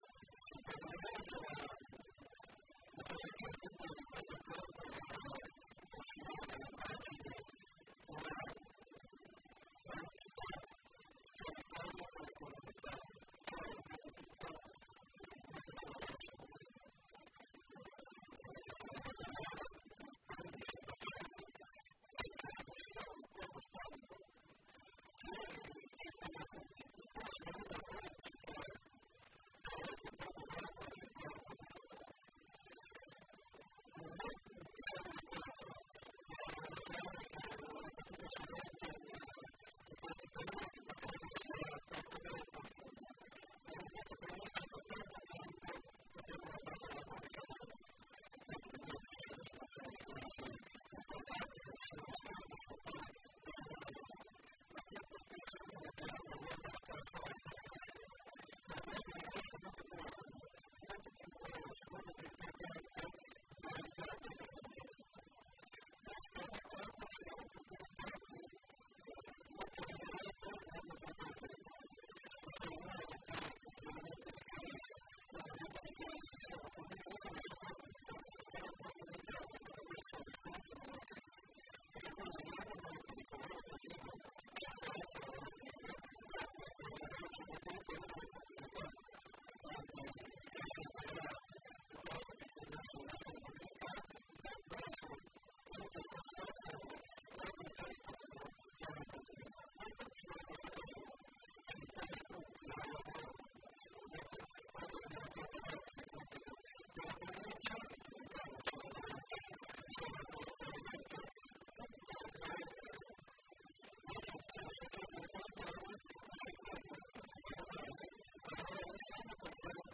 In fondo alla pagina, le parole del presidente della Provincia Angelo Vaccarezza.